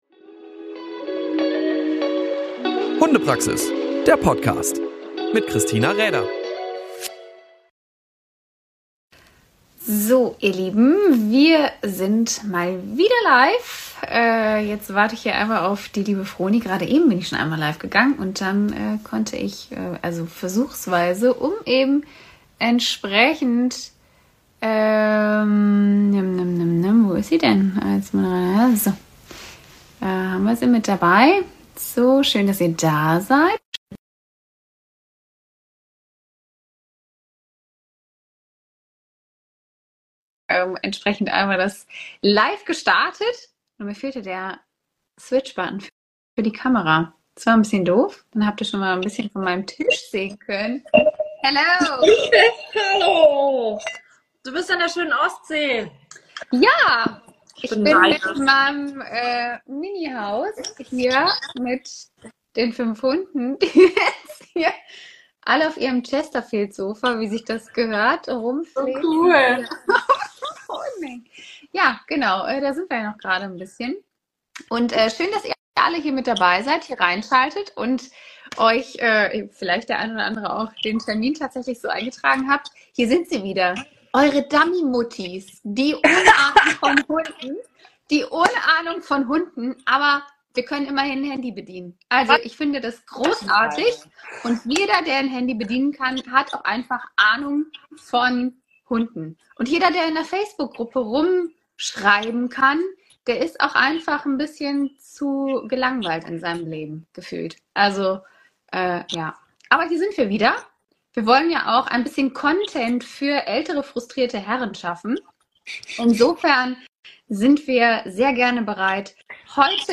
Die Folge gibt unser Insta-Live aus Februar wieder. Hier hatten wir eure Fragen rund um das Thema Mehrhundehaltung beantwortet. Und einen Gast hatten wir zum Ende hin auch noch - hört mal rein!